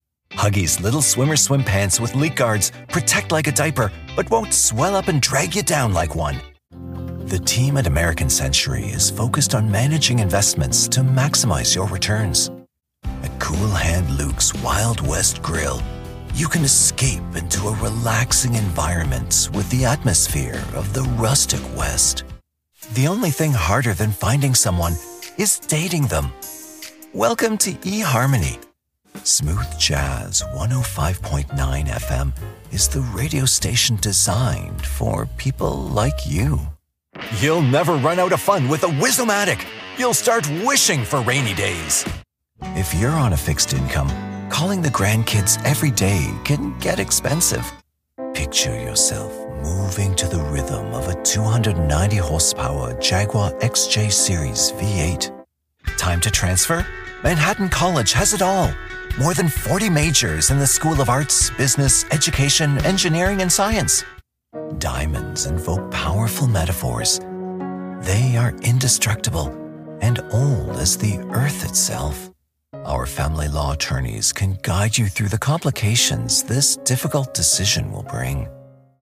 Commercial demo
Known for my warm, resonant baritone, I provide clear, trusted narration for commercials, web videos, eLearning, corporate content, explainers, IVR, animated characters, video games, and more.